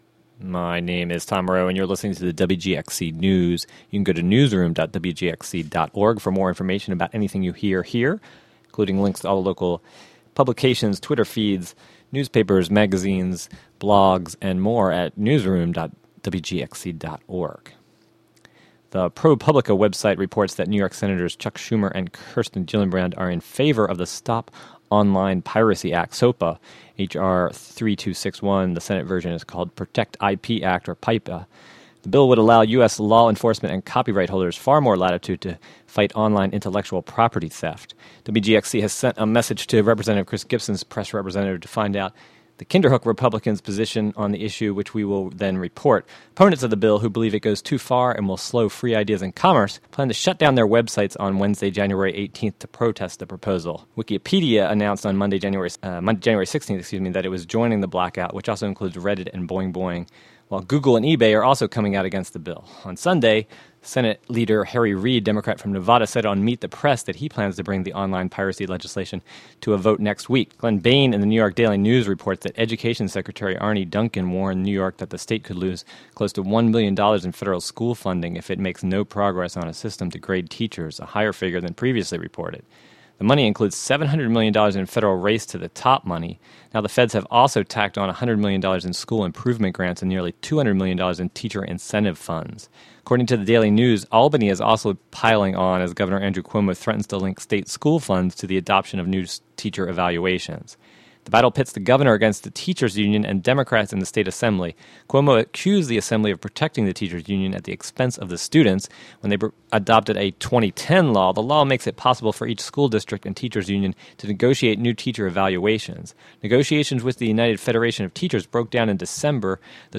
Clips from Gov. Andrew Cuomo, State Senator James Seward, and Kinderhook Supervisor/Columbia County Board of Supervisor Chairman Pat Grattan.